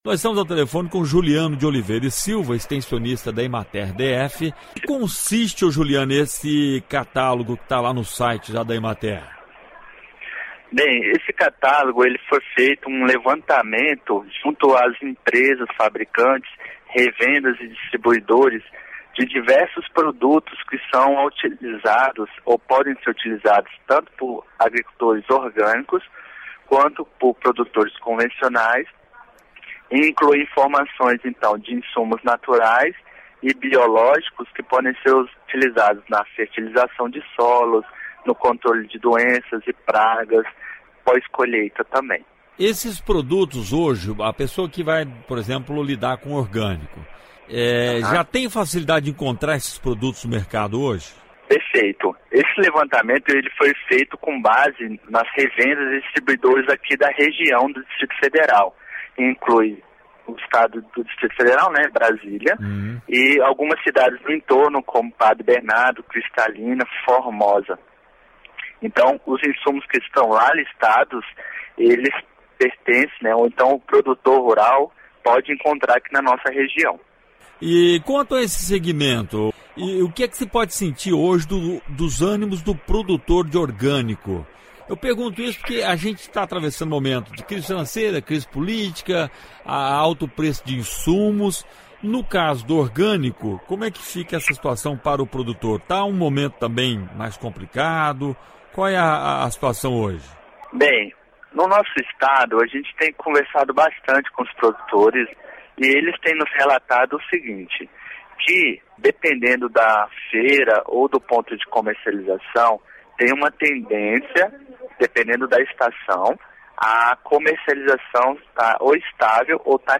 Entrevista: Saiba mais sobre o catálogo que ajuda produtores orgânicos